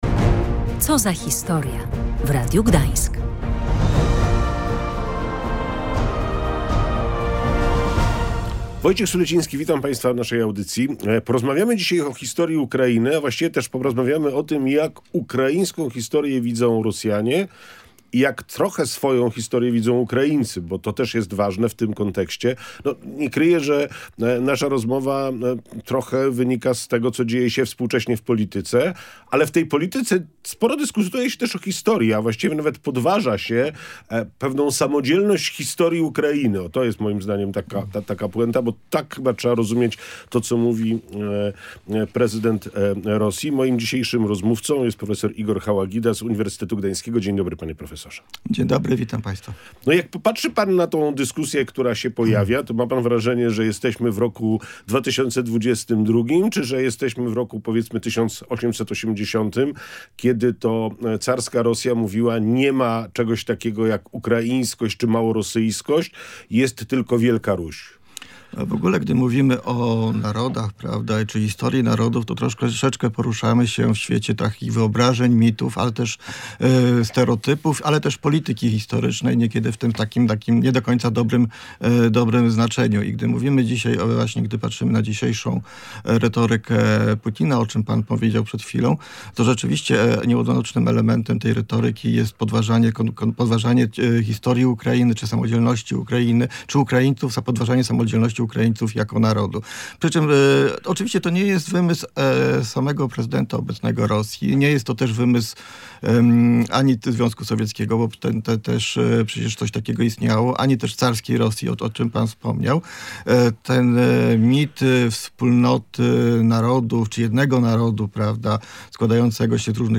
Rozmawiali o historii Ukrainy, o tym, jak ukraińską historię widzą Rosjanie, a także o tym, jak swoją historię widzą sami Ukraińscy. Bazą do rozmowy było tło historyczne, ale dyskusja prowadzona była także w kontekście tego, co współcześnie dzieje się w polityce.